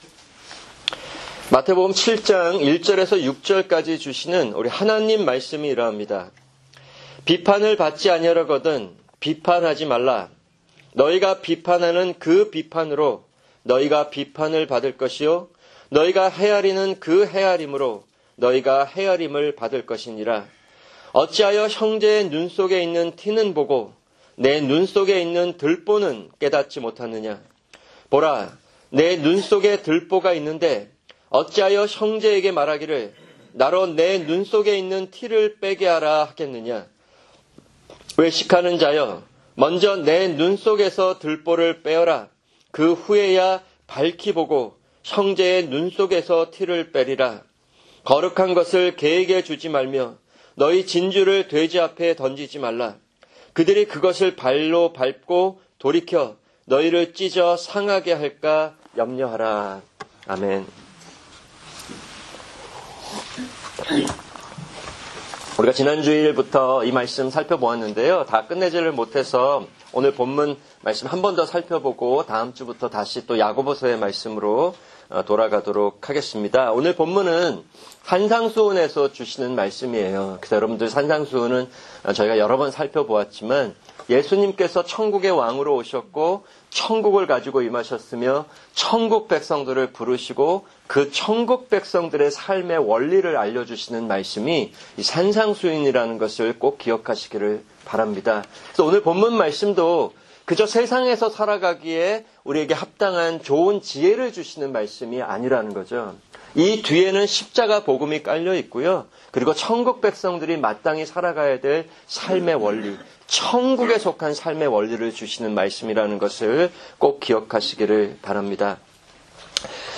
[주일 설교] 야고보서 1:9-11